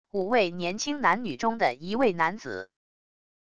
五位年轻男女中的一位男子wav音频